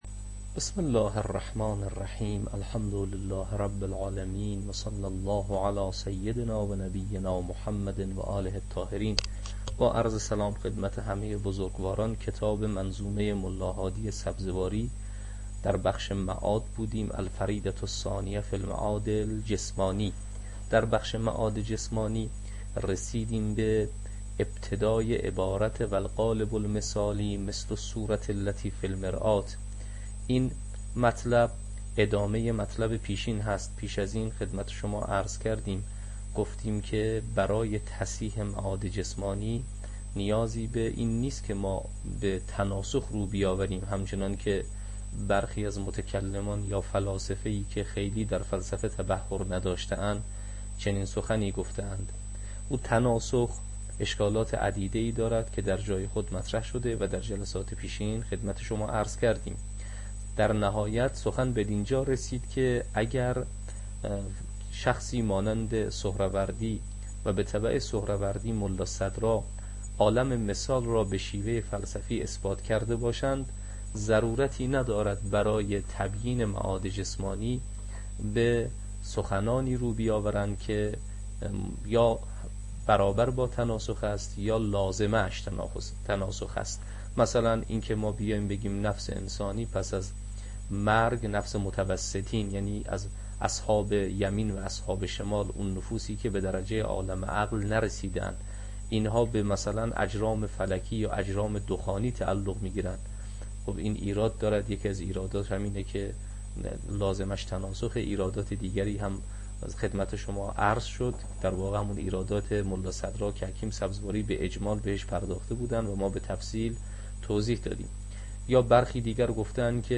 تدریس کتاب شرح منظومه